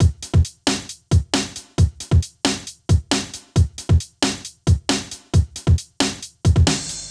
KIN Beat.wav